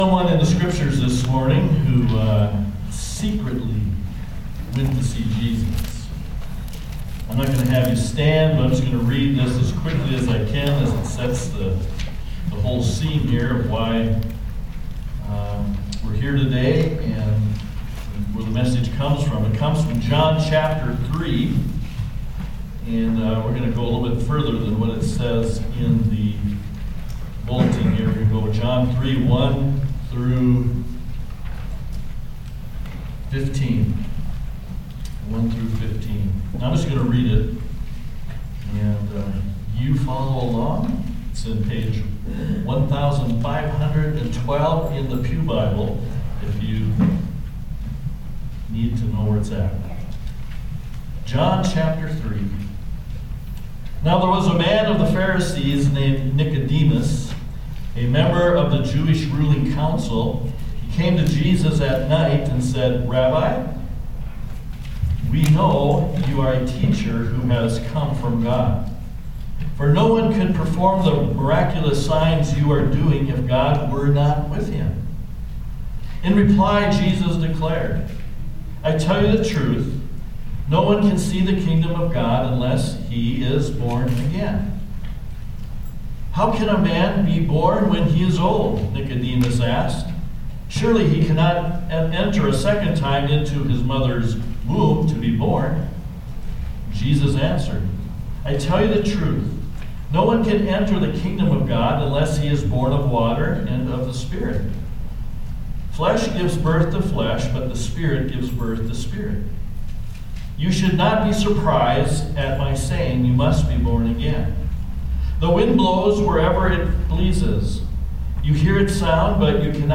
Bible Text: John 3:1-12 | Preacher